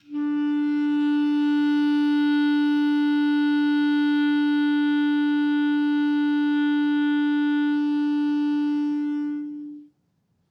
Clarinet
DCClar_susLong_D3_v3_rr1_sum.wav